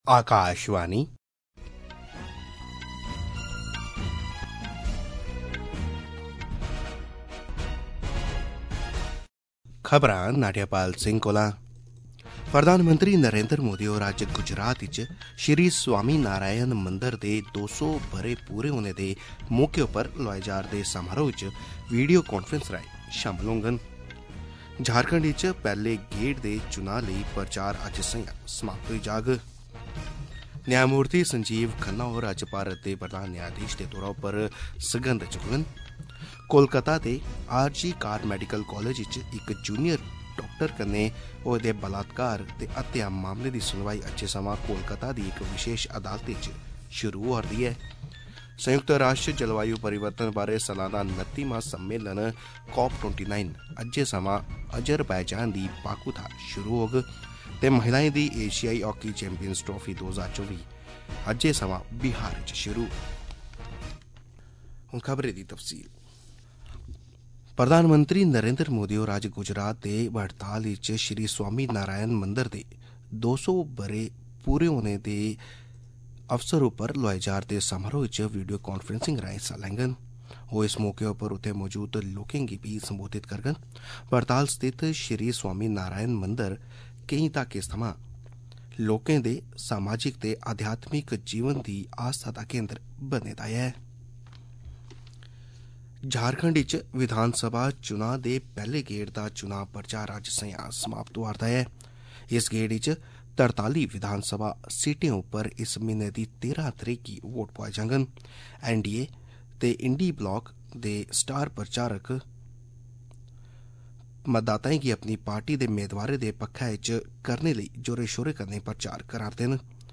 AUDIO-OF-MORNING-DOGRI-NEWS-BULLETIN-NSD-dogri.mp3